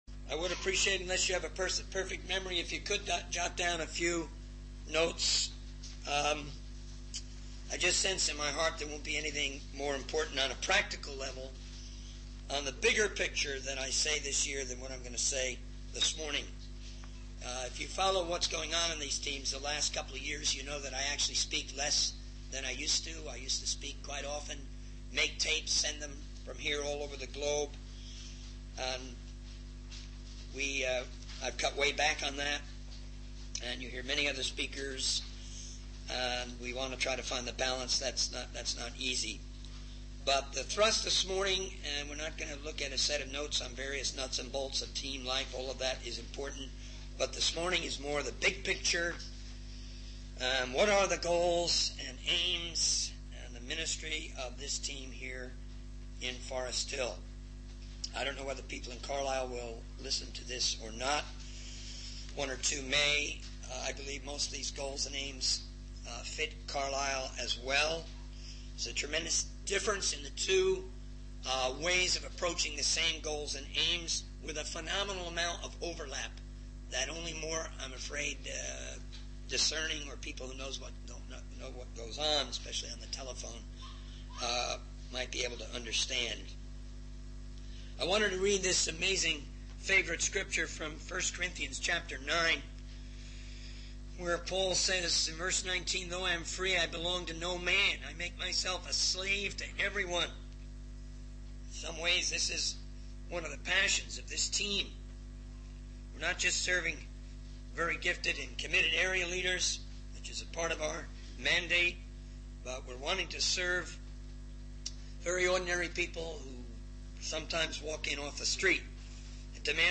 In this sermon, the speaker emphasizes the importance of having a lasting crown and not running aimlessly in our pursuit of goals. He mentions the 82,000 movement and how it aligns with the vision of the church. The speaker encourages individuals and departments within the church to have their own little visions and to actively work towards redeeming their time.